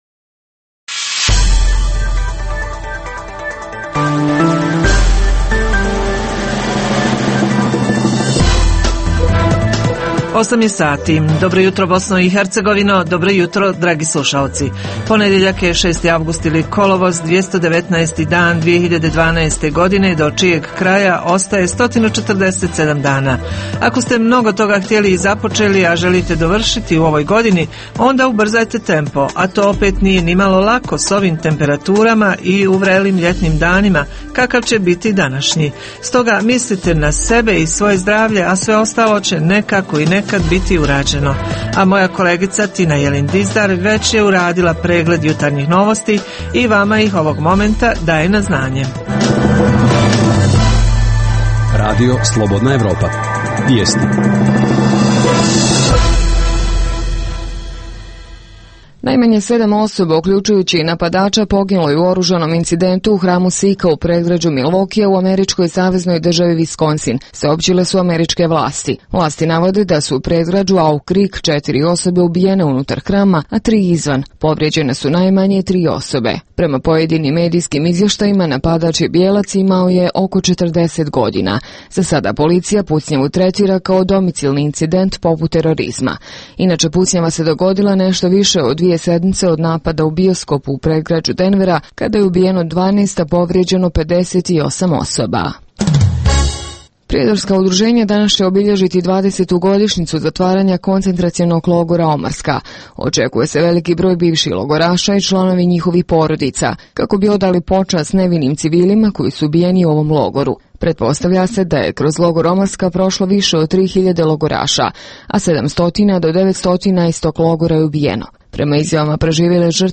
U „Olimpijskoj hronici“ pratimo natjecanja na Olimpijskim igrama u Londonu. Uz tri emisije vijesti, slušaoci mogu uživati i u ugodnoj muzici.